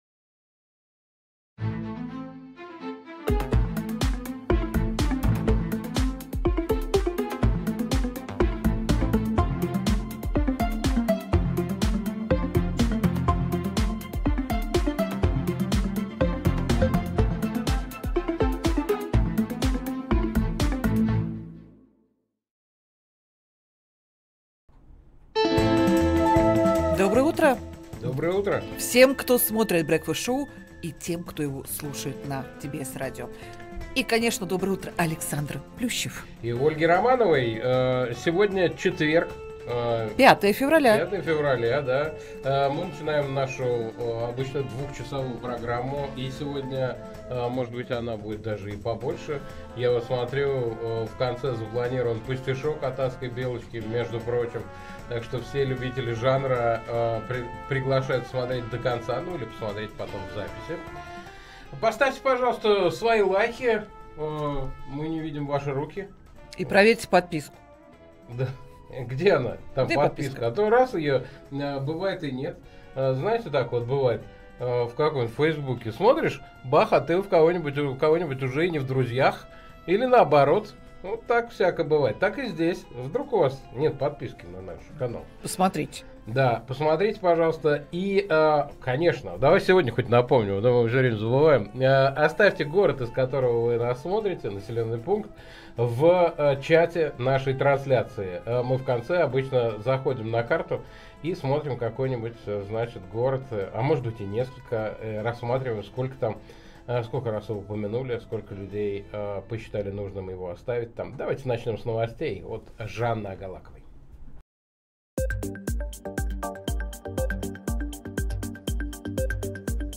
Утренний эфир с гостями
Александр Плющев и Ольга Романова обсудят с экспертами в прямом эфире The Breakfast Show все главные новости.